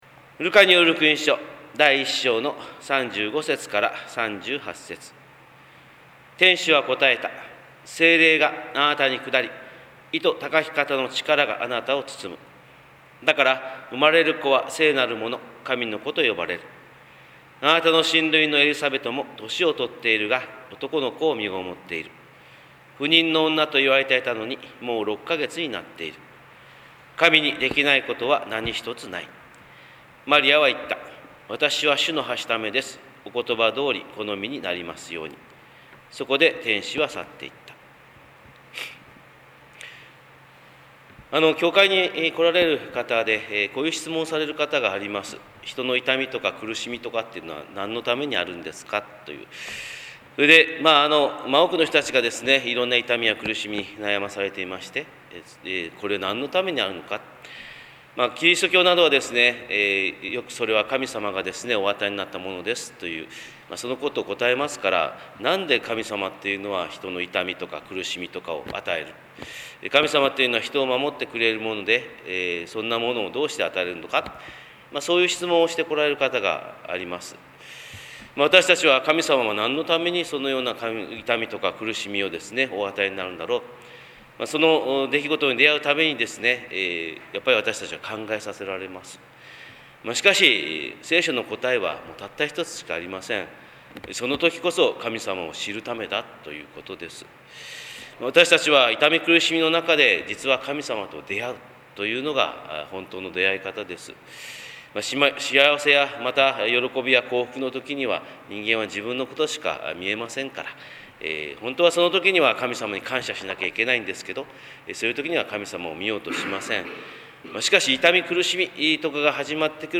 神様の色鉛筆（音声説教）
日本福音ルーテル教会（キリスト教ルター派）牧師の朝礼拝説教です！